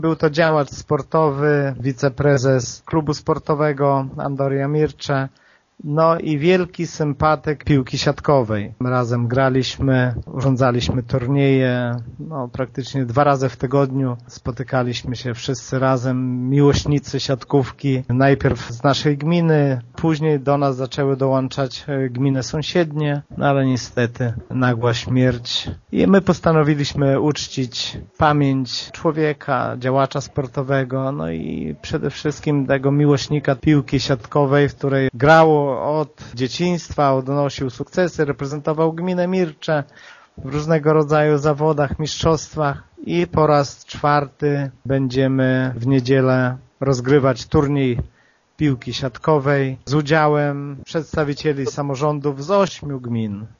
Bolesław Choma był wielkim miłośnikiem siatkówki – wspomina wójt Lech Szopiński: